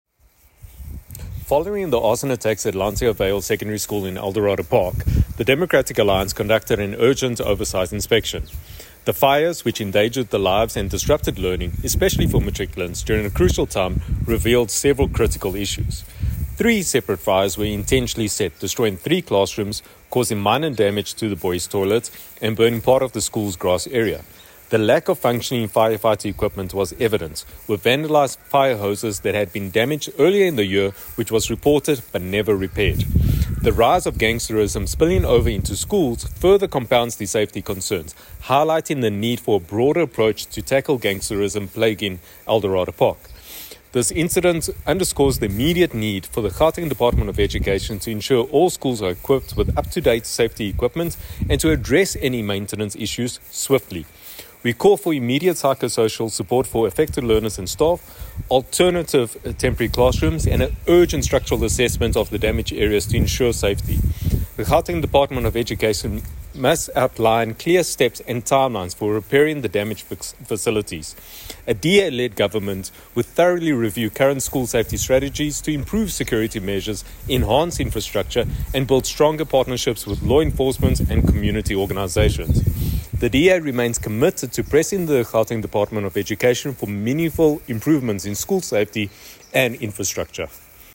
soundbite by Sergio Isa Dos Santos MPL.